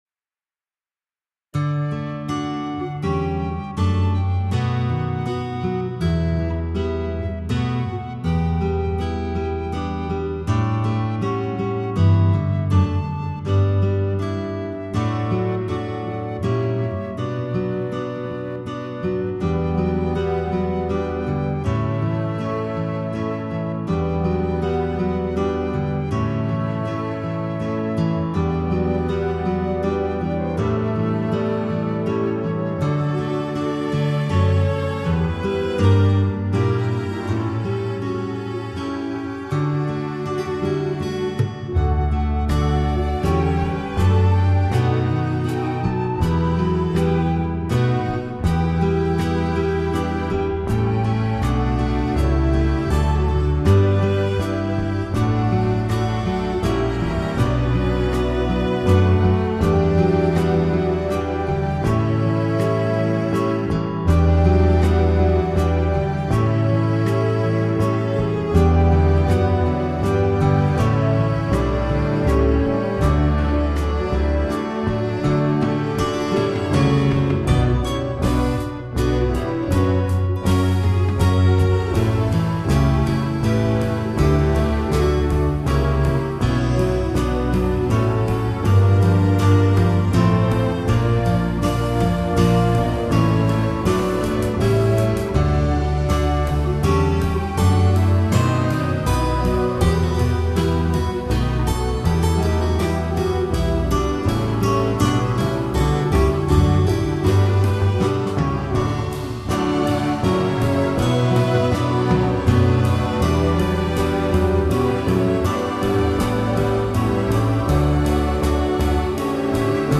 Here’s a tune I wrote on my acoustic guitar.
It’s all virtual instruments done in the piano roll of Sonar 8.5.